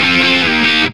Index of /90_sSampleCDs/Roland LCDP02 Guitar and Bass/GTR_GTR FX/GTR_Gtr Hits 1